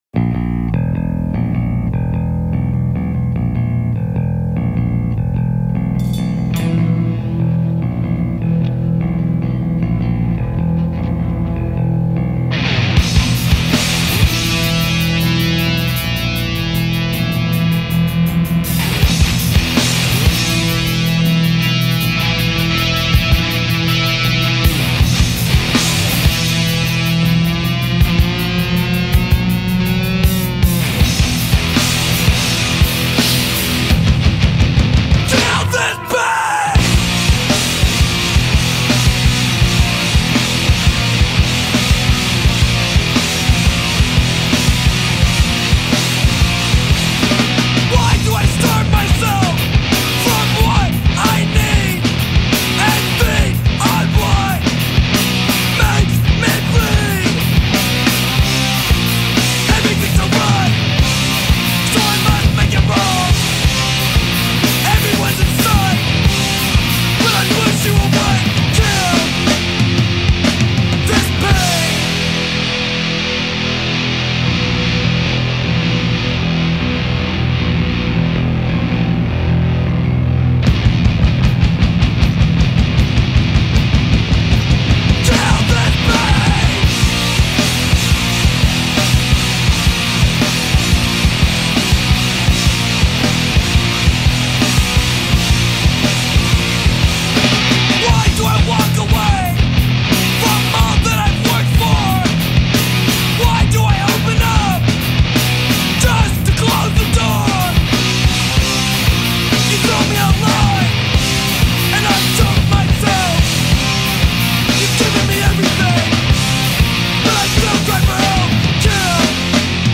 90's Straight edge hardcore, Metalcore from Seattle